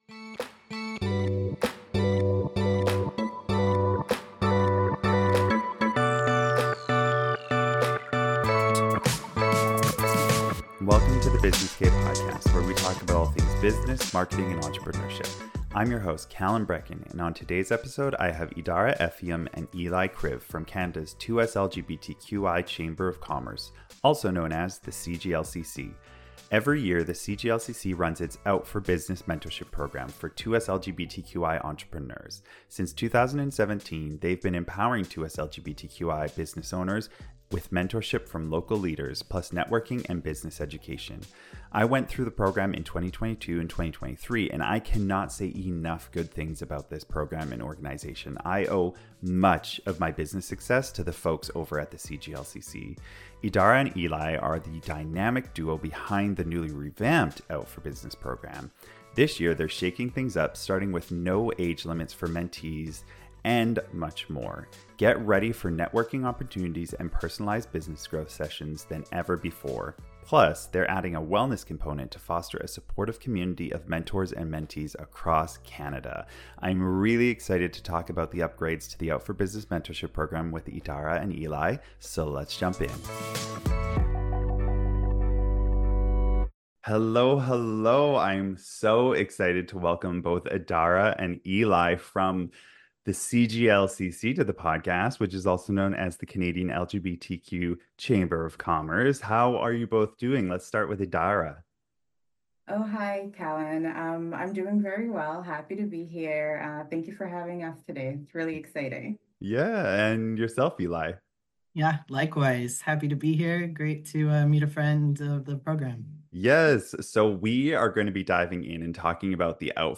Every year Canada's 2SLGBTQI+ Chamber of Commerce (CGLCC) runs its “OUT for Business” mentorship program for 2SLGBTQI+ entrepreneurs. This is a detailed interview about the program and what you can expect if you apply.